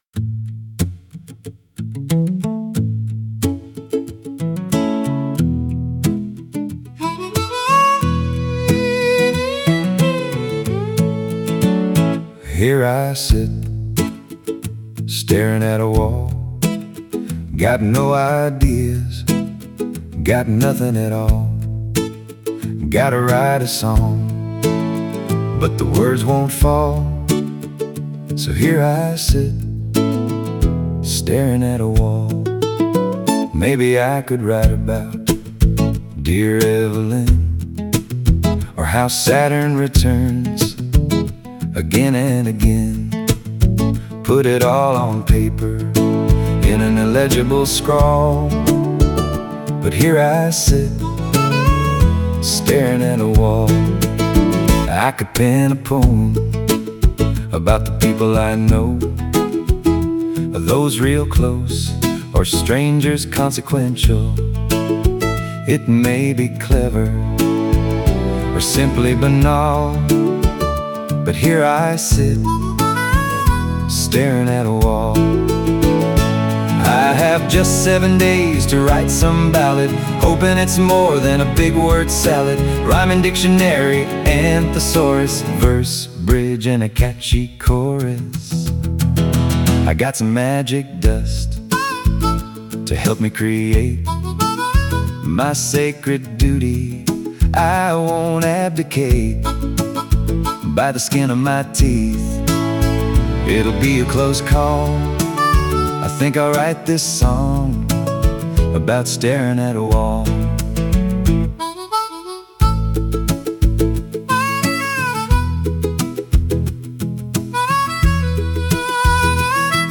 I really like the John Prine feel of this; I’m going to make another, more universal, version of it with my own voice when I get a minute. (I’m up at the Interlochen Center for the Arts in Northern Michigan to teach a full day of ukulele workshops tomorrow, so I just used the Suno version.)